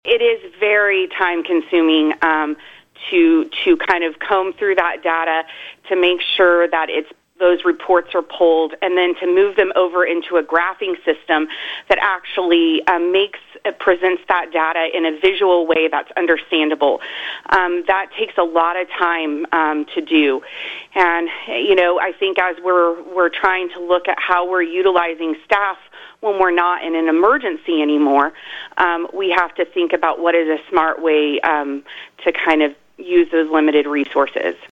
on-air interview